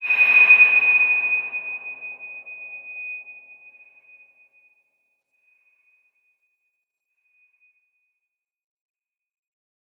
X_BasicBells-D#5-mf.wav